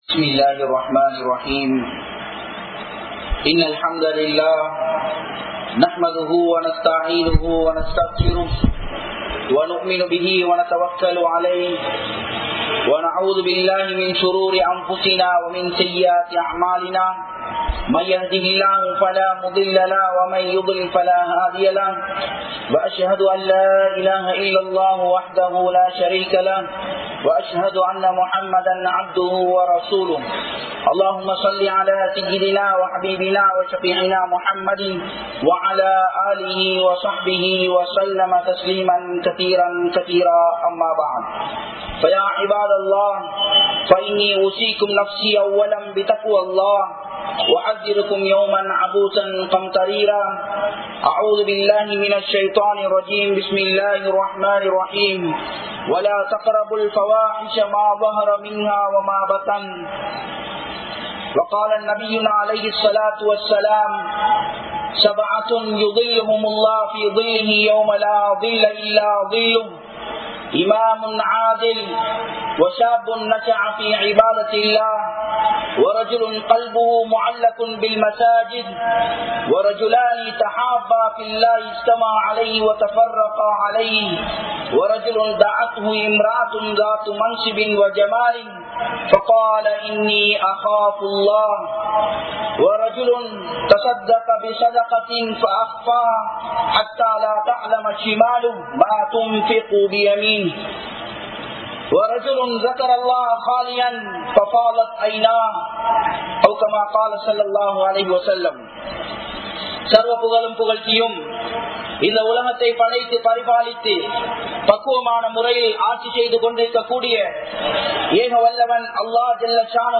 Paavaththin Pakkam Nerunkaatheerkal!(பாவத்தின் பக்கம் நெருங்காதீர்கள்!) | Audio Bayans | All Ceylon Muslim Youth Community | Addalaichenai
Saliheen Jumua Masjidh